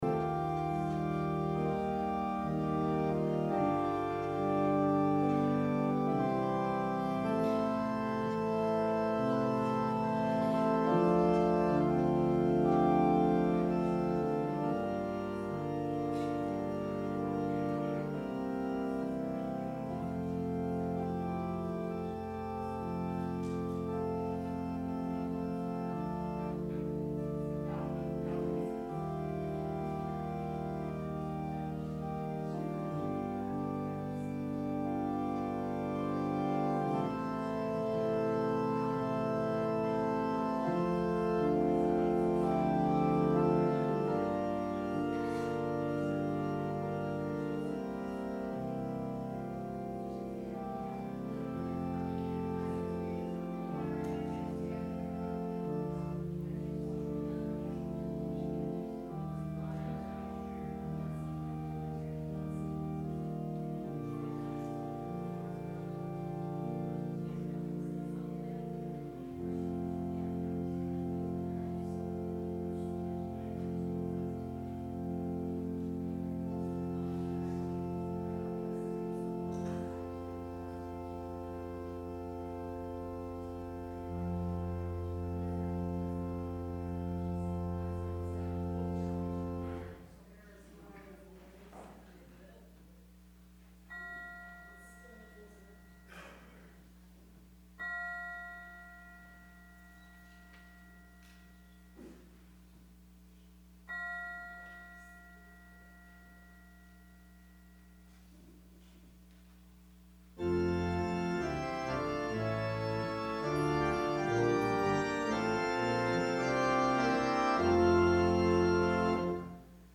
Sermon – September 1, 2019